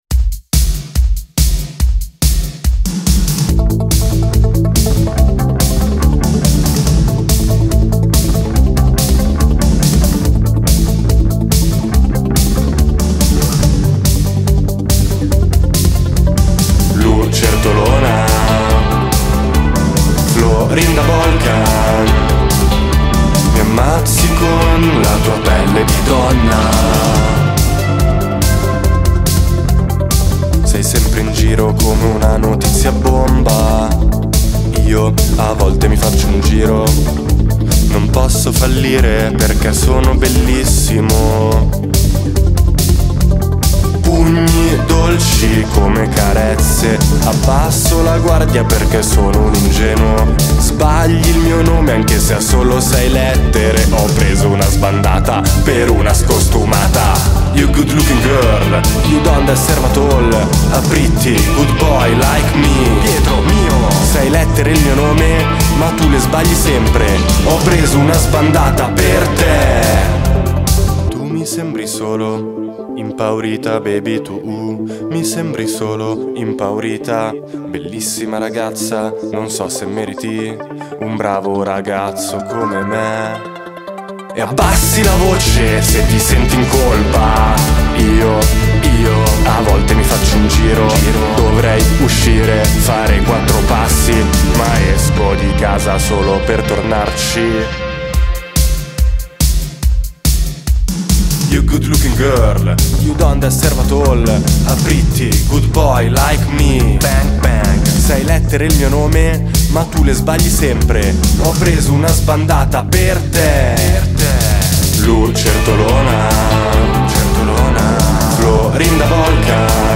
“intonato quanto basta”